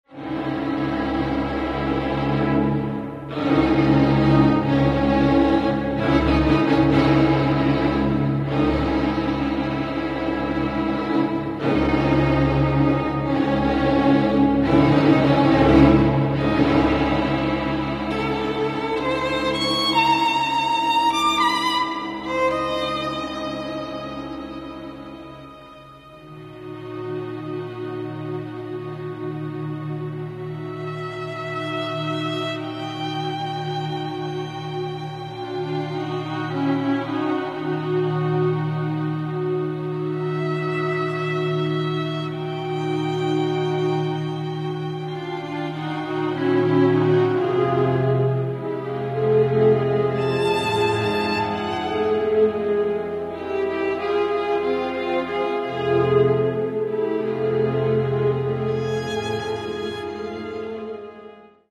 Catalogue -> Classical -> Chamber
violin
for violin and chamber orchestra